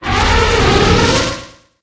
Cri de Pachyradjah dans Pokémon HOME.